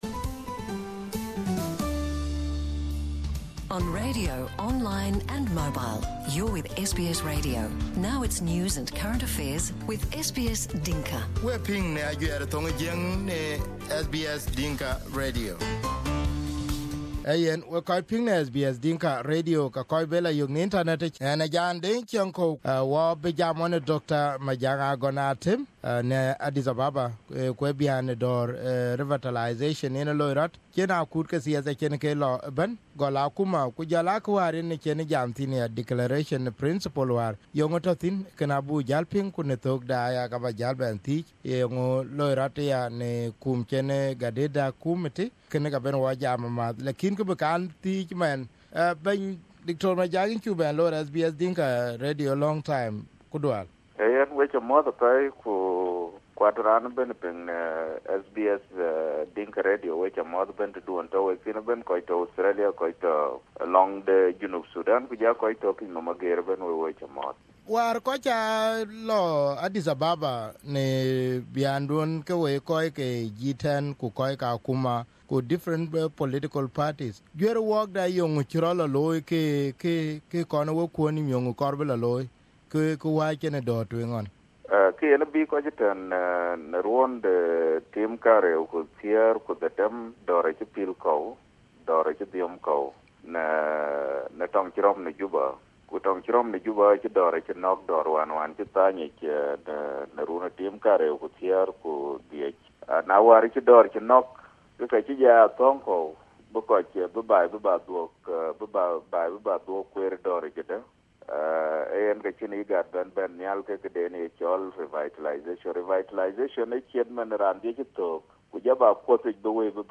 Here is the interview with Dr.Majak and what is going on and we ask him if there is hope?